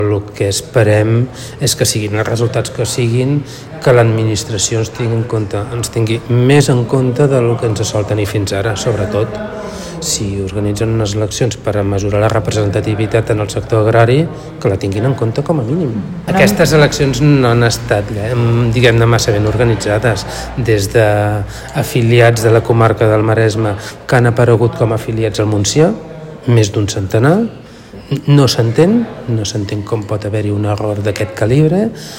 A peu d'urna, instal·lada al Saló de Pedra, diversos pagesos coincidien en el diagnòstic.